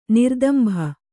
♪ nirdambha